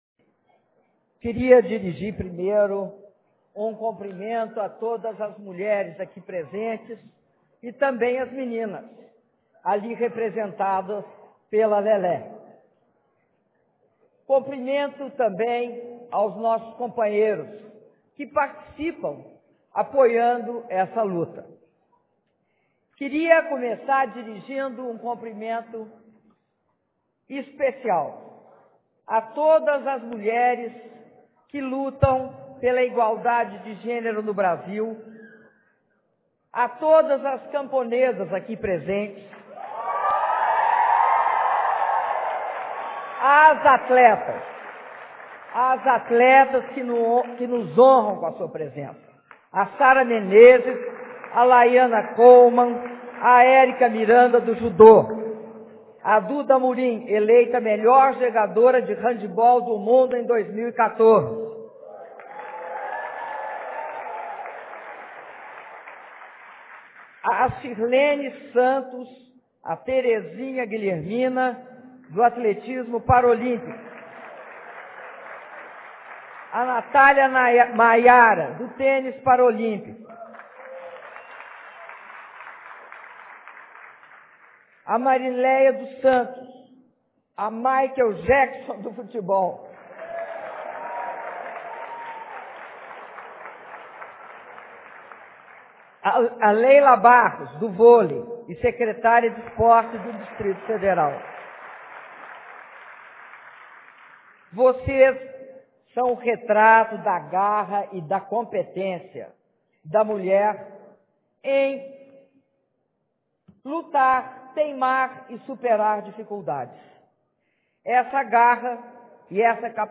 Áudio do discurso da Presidenta da República, Dilma Rousseff, durante a cerimônia de sanção da Lei de Tipificação do Feminicídio (27min30s)